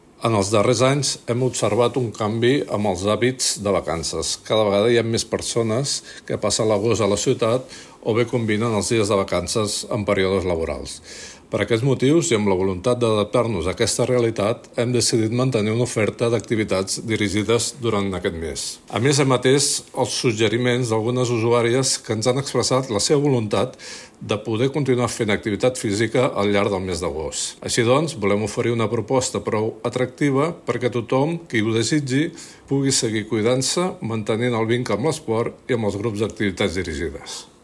Gerard Mimó, regidor d'Esports de l'Ajuntament de Martorell